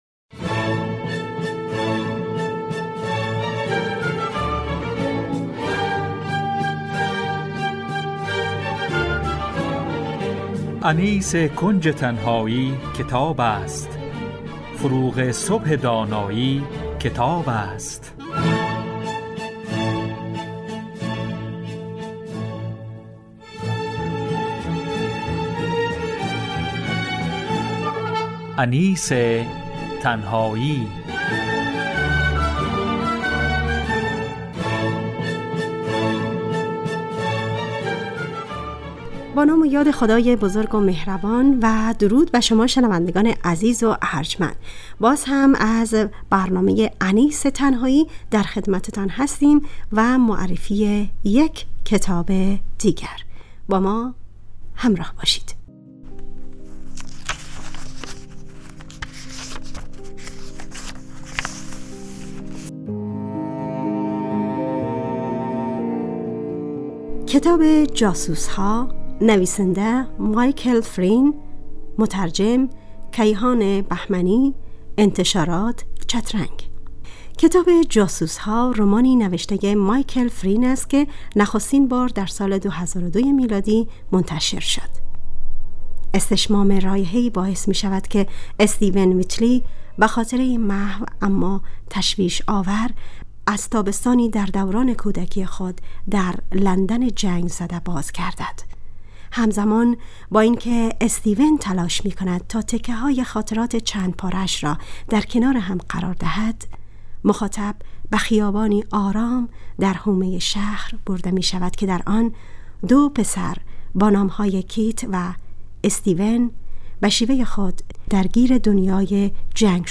معرفی کتاب
همراه با گزیده ای از سطرهایی از کتاب